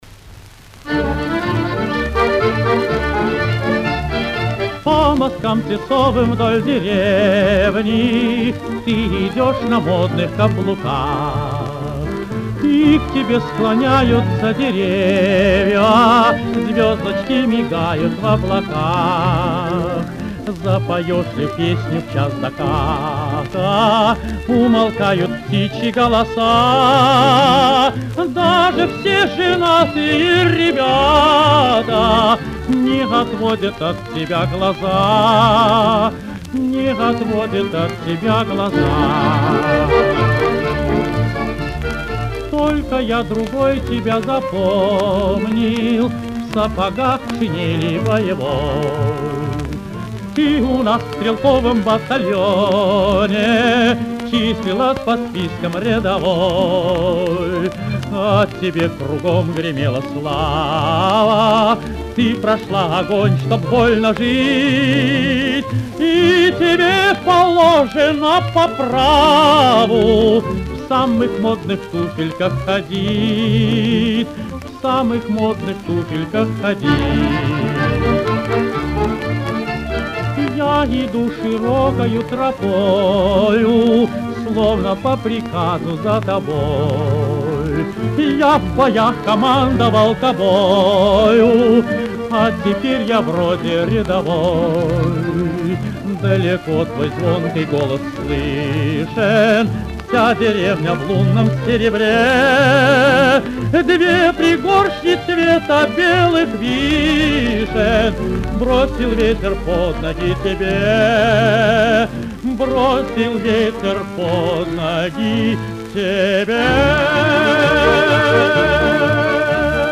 Каталожная категория: Тенор с оркестром |
Жанр: Песня
Место записи: Ленинград |